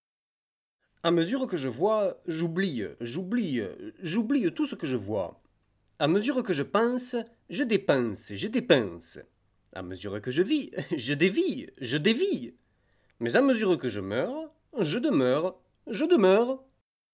young, dynamic, sport, colourful voice, frenche , französisch, deutsch mit Akzent, English with french accent
Sprechprobe: Sonstiges (Muttersprache):
young, dynamic and colourful voice